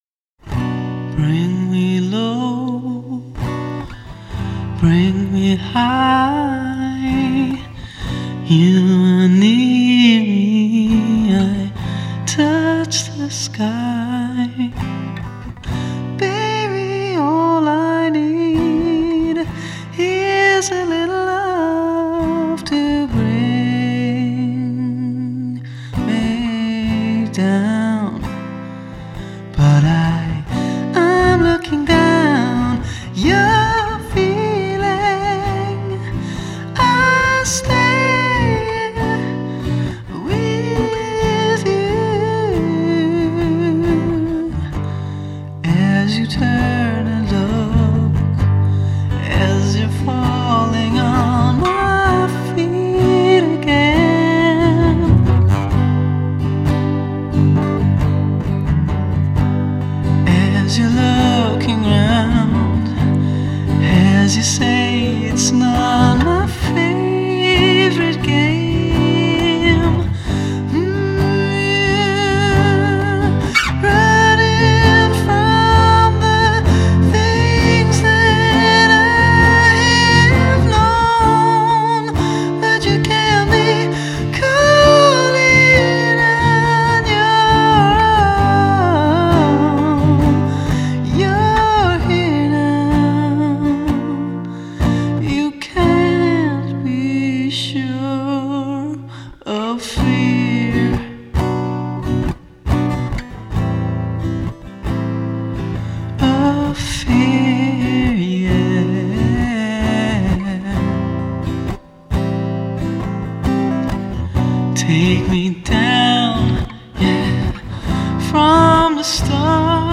genre: Acoustic